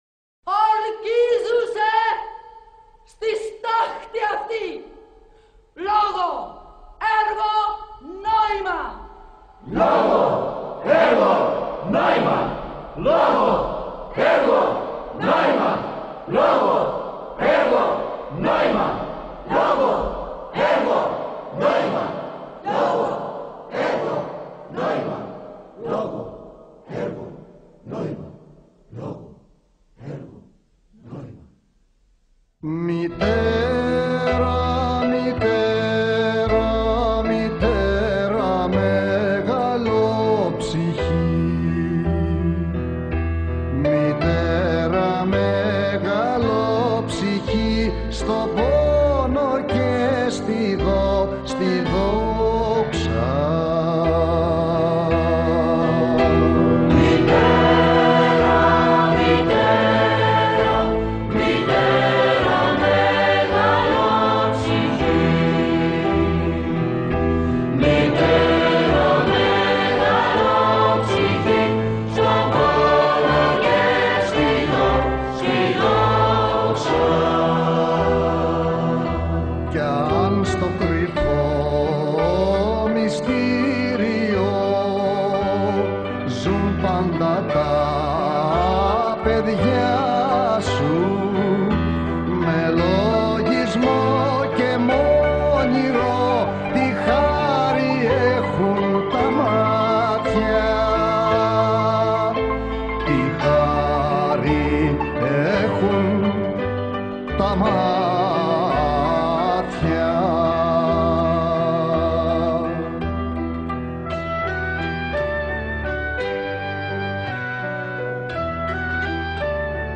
Εκφώνηση: